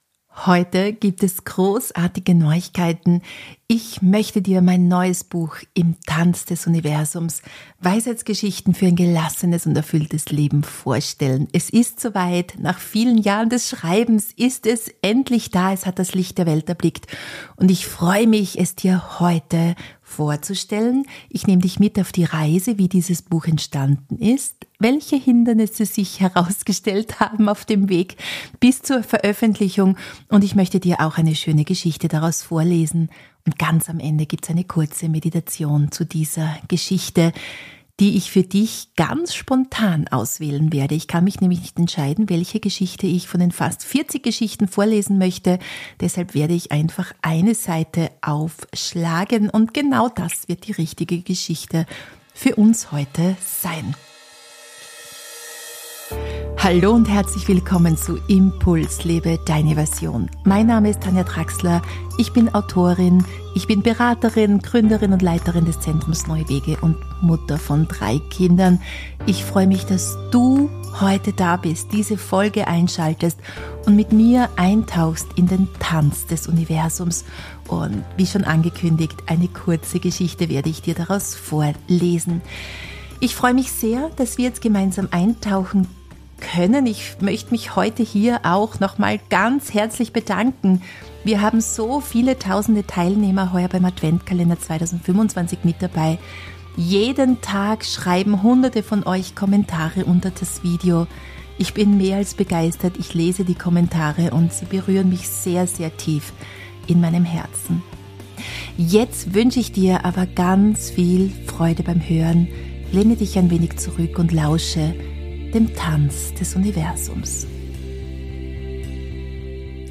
Du erfährst, wie einzelne Geschichten gewachsen sind, was mich beim Schreiben tief berührt hat – und warum wir manchmal erst loslassen müssen, um tanzen zu können. Ich lese eine Weisheitsgeschichte vor und führe dich anschließend in eine kurze Meditation, die dich einlädt, deinen eigenen inneren Rhythmus wiederzufinden.